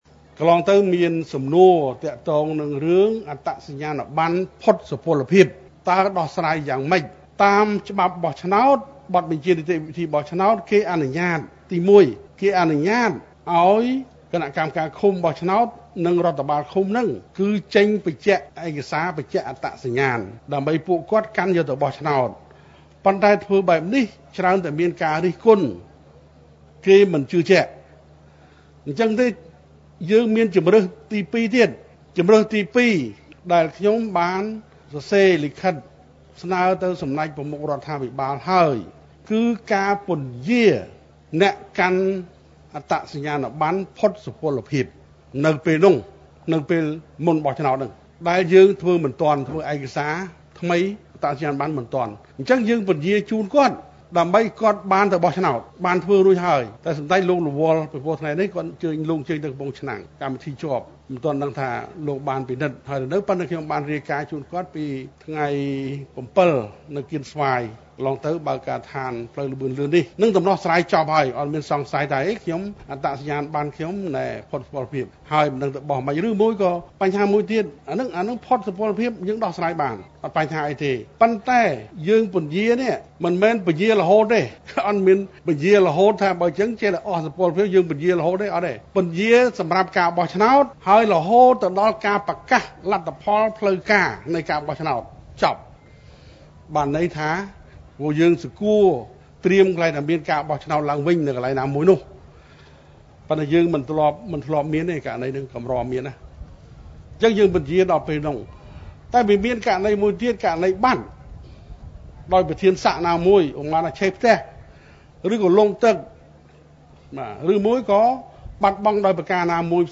ក្នុងពិធីបើកការដ្ឋានលើកកម្រិតជួសជុល និងថែទាំផ្លូវខេត្តលេខ៣១ក្នុងខេត្តព្រៃវែង នៅថ្ងៃទី០៩ ខែមិថុនា ឆ្នាំ២០២៣នេះ សម្ដេចក្រឡាហោម ស ខេង រដ្ឋមន្រ្តីក្រសួងមហាផ្ទៃ បានបញ្ជាក់ថា លោកបានដាក់សំណើទៅសម្ដេចតេជោ ហ៊ុន សែនរួចហើយ ដើម្បីពិនិត្យលទ្ធភាពក្នុងការពន្យារពេលសម្រាប់ប្រជាពលរដ្ឋដែលកំពុងប្រើប្រាស់អត្តសញ្ញាណប័ណ្ណផុតសុពលភាព អាចឱ្យពួកគេប្រើក្នុងការបោះឆ្នោតជ្រើសតាំងតំណាងរាស្រ្ត នីតិកាលទី៧ នាពេលខាងមុខនេះ ដោយសារពួកគេធ្វើអត្តសញ្ញាណប័ណ្ណថ្មីមិនទាន់។ ការបញ្ជាក់របស់លោករដ្ឋមន្រ្តីក្រសួងមហាផ្ទៃ ធ្វើឡើងនៅ។
សូមស្ដាប់ប្រសាសន៍សម្ដេចក្រឡាហោម…